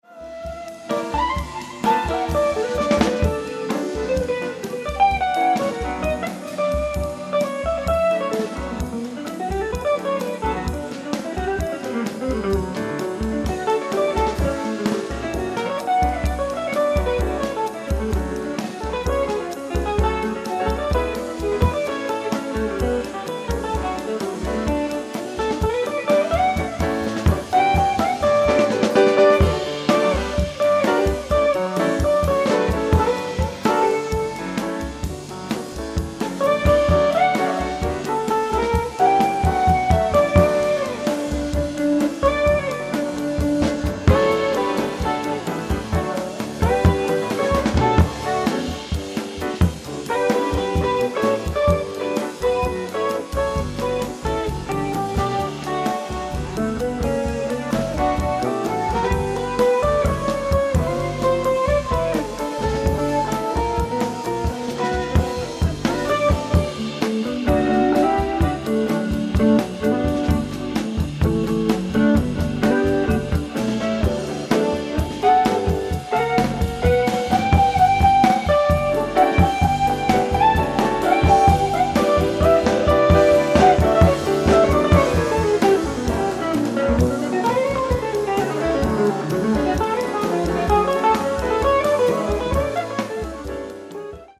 ライブ・アット・ピッツバーグ、ペンシルバニア 03/16/1981
※試聴用に実際より音質を落としています。